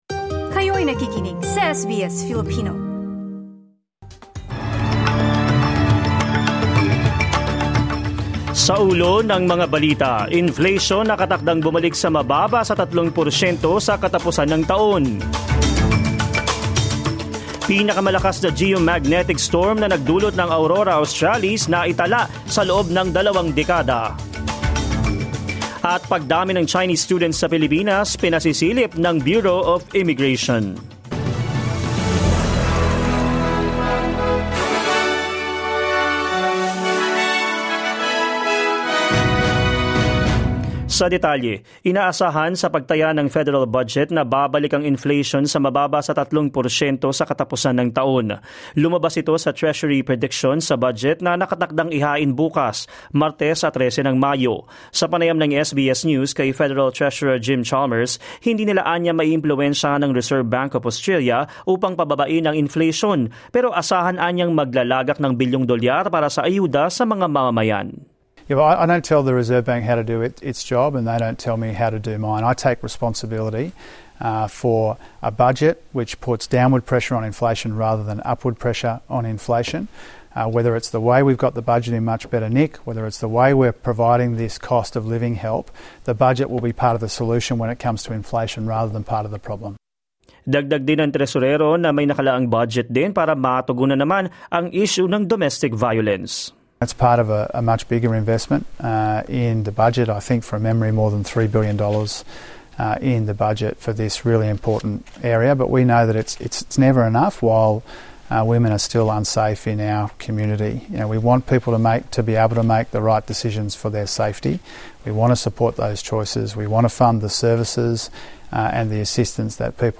SBS News in Filipino, Monday 13 May 2024